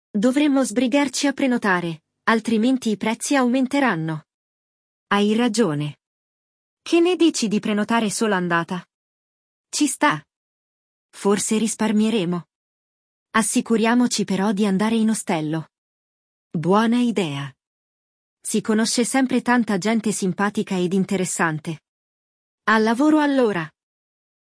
No episódio do Walk ’n’ Talk Essentials italiano de hoje, vamos escutar uma conversa entre a Sofia e o Marco, na qual eles falam sobre as férias e, mais exatamente, sobre qual hospedagem escolher para não gastar dinheiro demais!